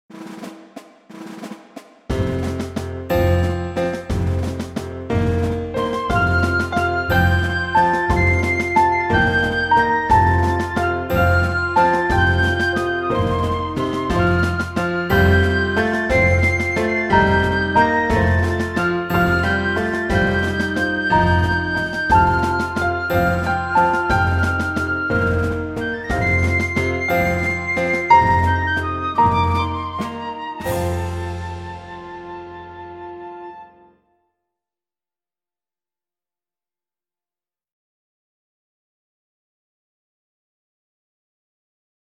Pr. Accomp